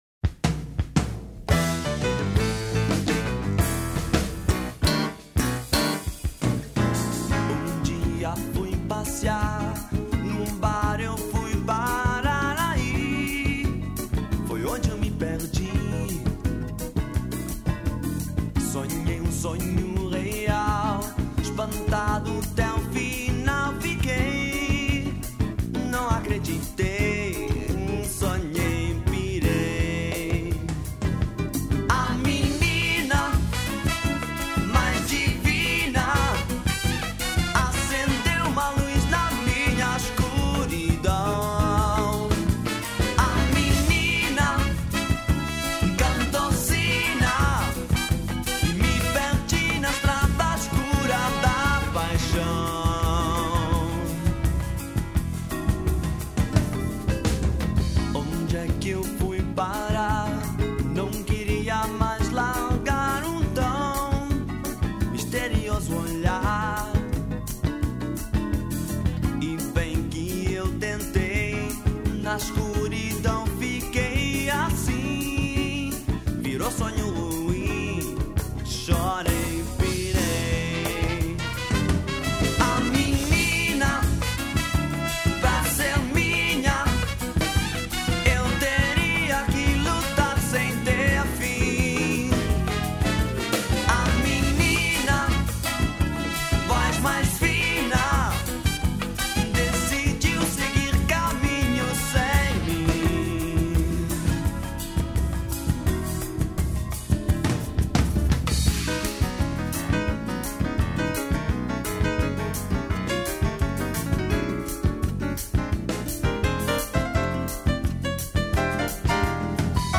Vocals, Guitar
Keyboards
Bass
Percussion
Drums